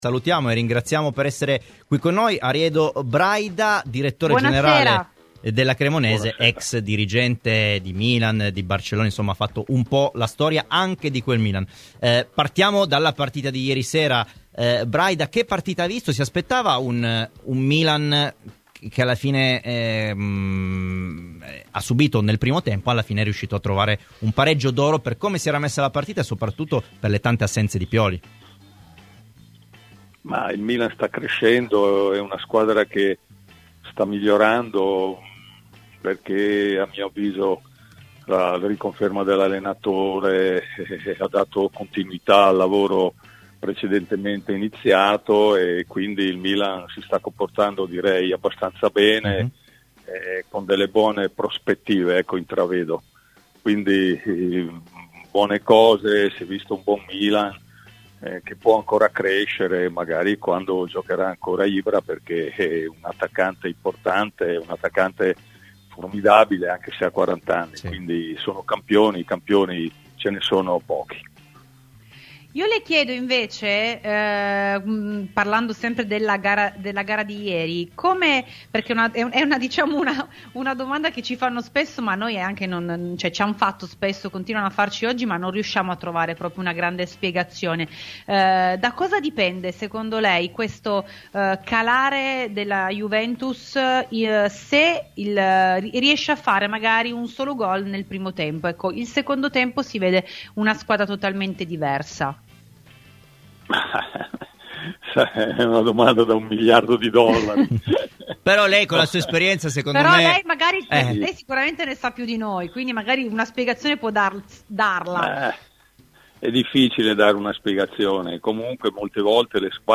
Ai microfoni di Radio Bianconera, nel corso di ‘Terzo Tempo’